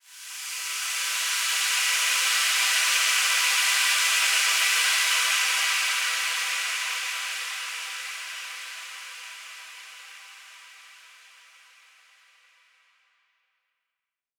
SaS_HiFilterPad04-C.wav